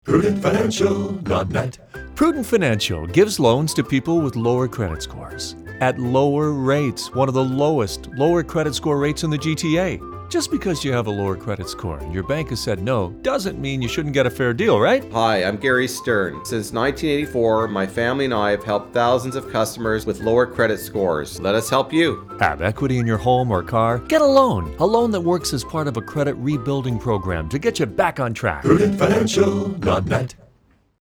We have three spots playing on 680 News, Toronto’s all-news radio.